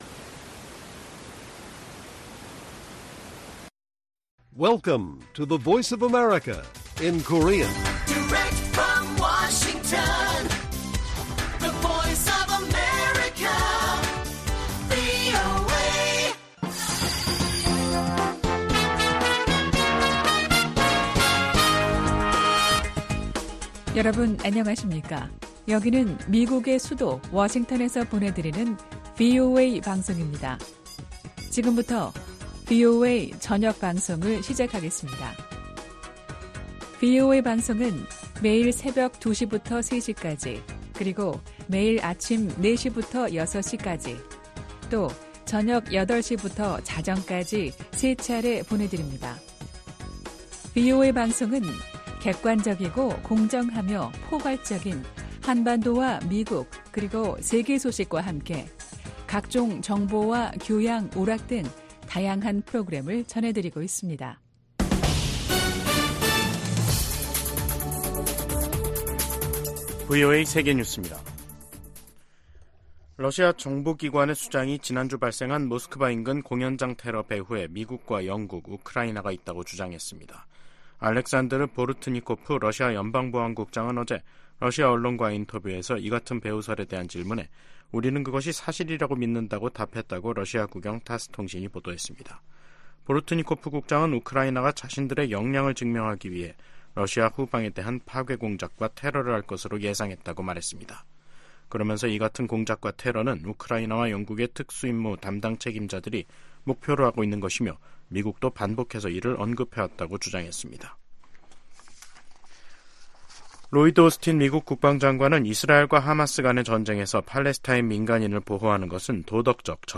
VOA 한국어 간판 뉴스 프로그램 '뉴스 투데이', 2024년 3월 27일 1부 방송입니다. 북한의 김여정 노동당 부부장이 일본과의 정상회담과 관련, 어떤 교섭이나 접촉도 거부할 것이라고 밝혔습니다. 미국 정부가 북한-러시아 군사 협력에 우려를 거듭 표명하고, 북한 무기가 무고한 우크라이나인 살해에 사용되고 있다고 지적했습니다. 기시다 후미오 일본 총리의 다음달 국빈 방미를 계기로 미일 군사 안보 동맹이 업그레이드 될 것으로 전문가들은 전망하고 있습니다.